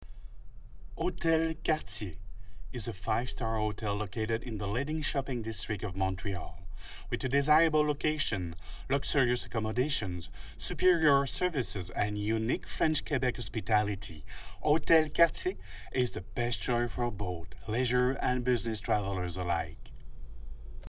Man
kanadisch-fr
Sprechprobe: Sonstiges (Muttersprache):